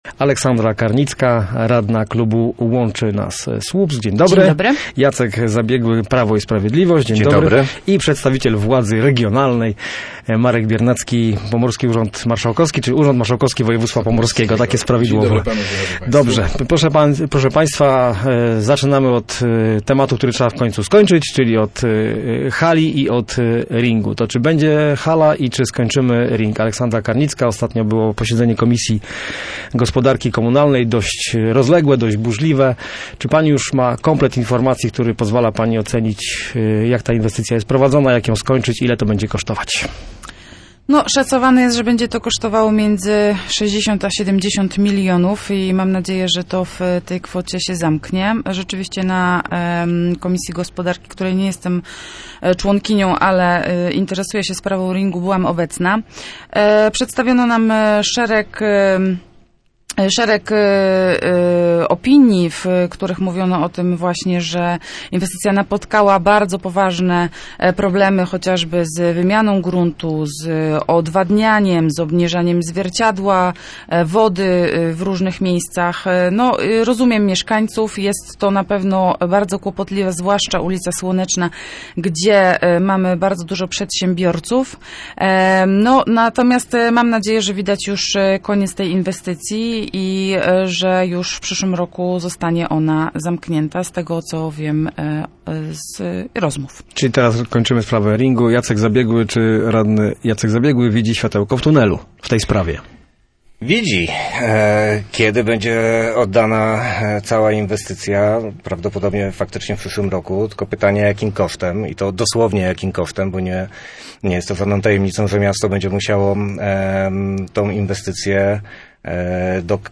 Samorządowcy byli gośćmi miejskiego programu Radia Gdańsk Studio Słupsk 102 FM.